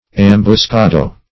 Ambuscado \Am`bus*ca"do\